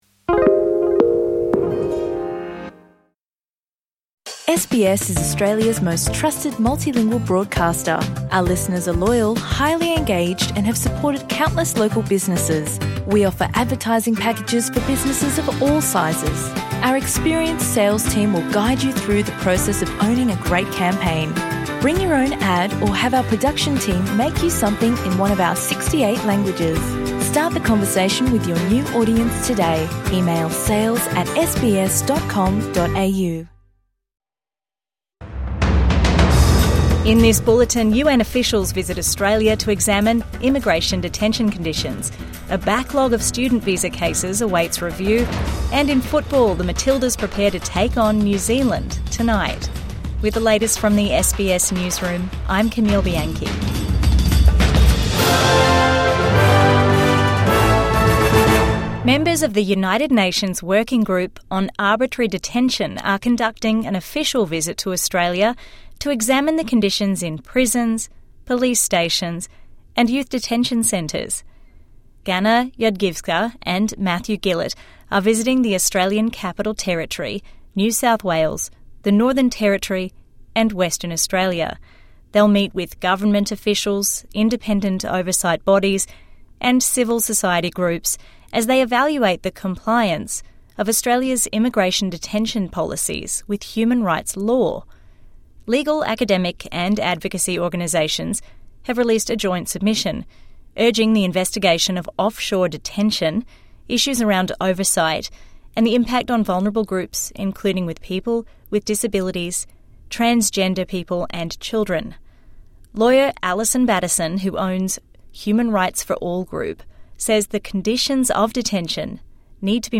UN group to visit Australian detention centres | Midday News Bulletin 2 December 2025